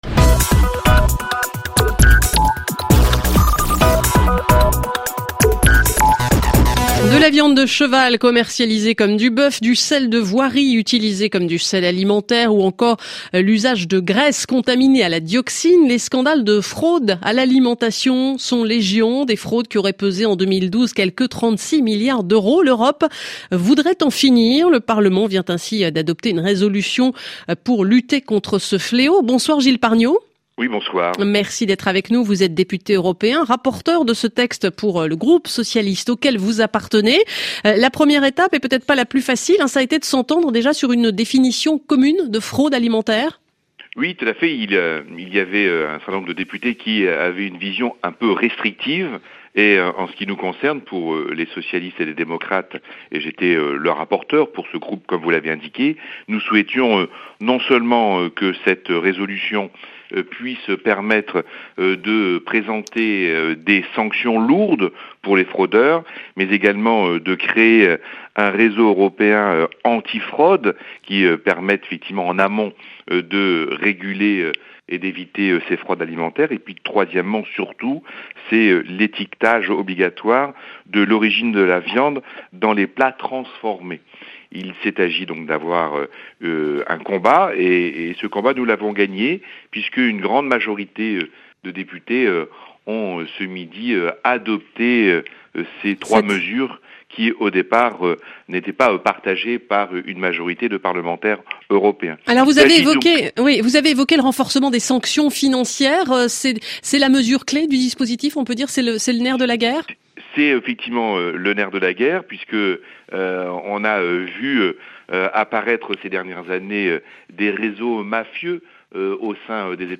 RFI : Interview sur la fraude alimentaire en Europe